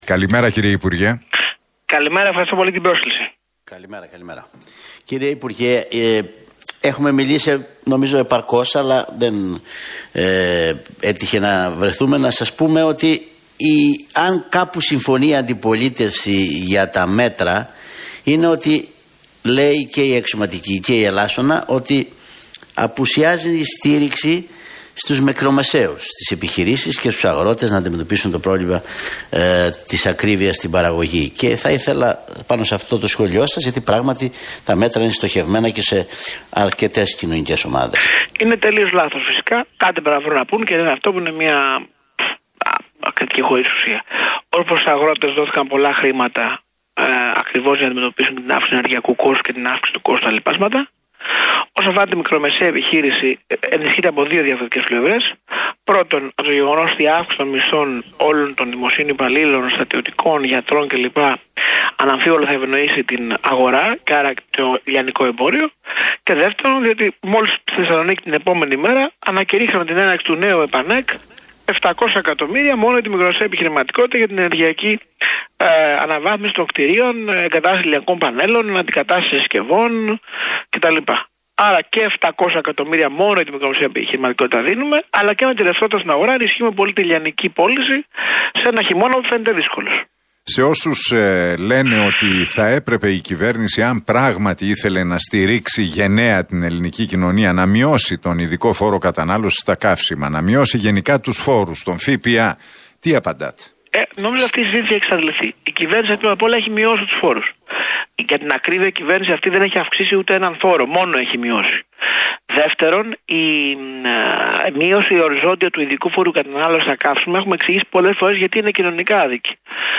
Γεωργιάδης στον Realfm 97,8: Πολιτική τερατογένεση η συνεργασία ετερόκλητων δυνάμεων για να εμποδίσουν τον νικητή των εκλογών
Ο υπουργός Ανάπτυξης και Επενδύσεων, Άδωνις Γεωργιάδης, μιλώντας στον Realfm 97,8